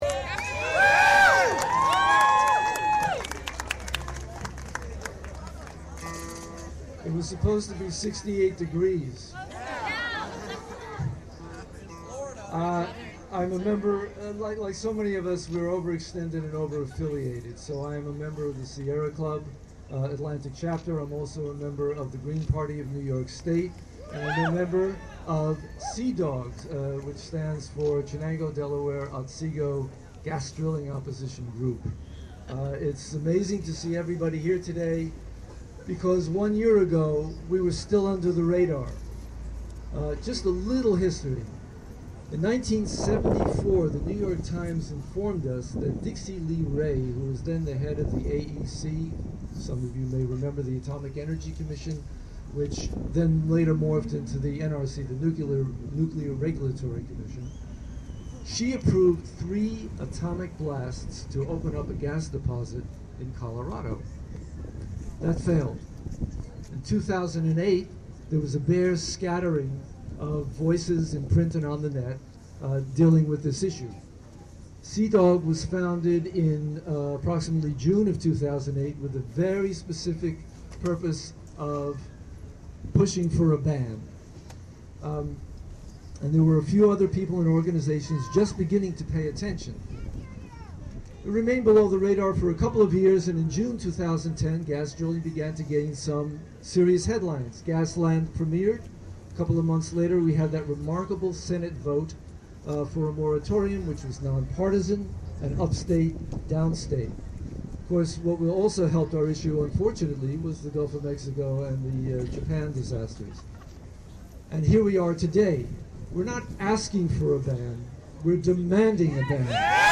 speaking at Albany Earth Day rally May 2.